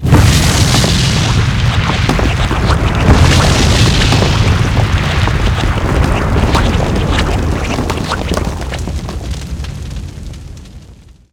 volcano.ogg